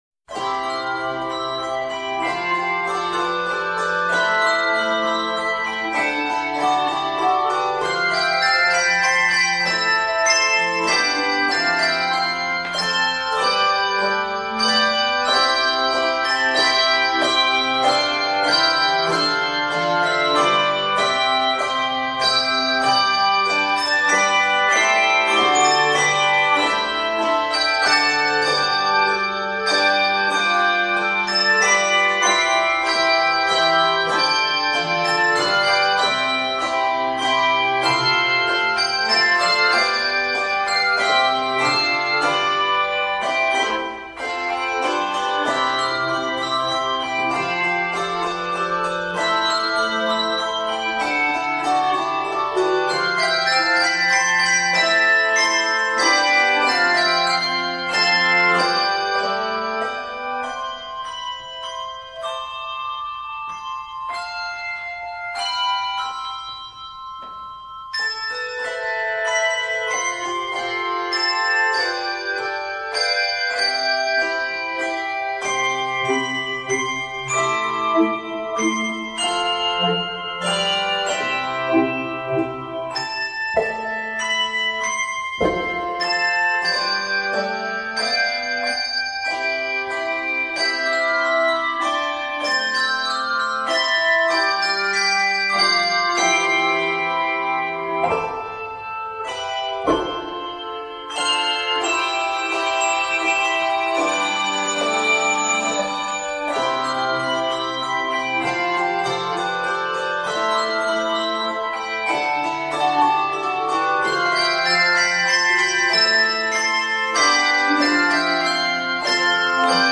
a waltz-like presentation
it is scored in C Major.